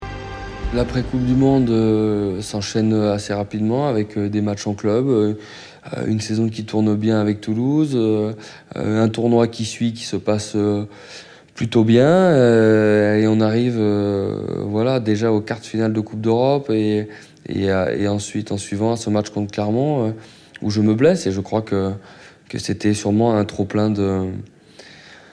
Une petite voix de sportif, ça fait longtemps ;)
On est donc dans le rugby, un ailier français de Toulouse